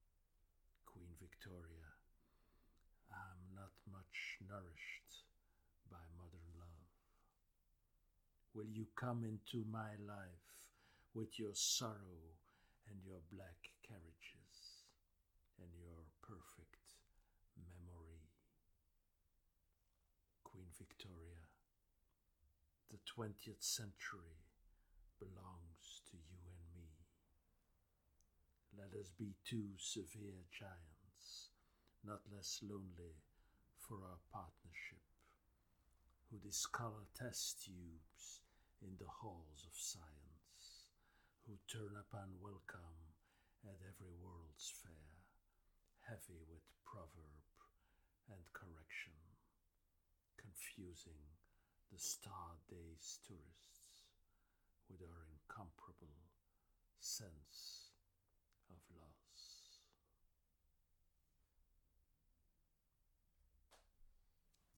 muziek repetitie 16 oktober – cie M.E.S.S.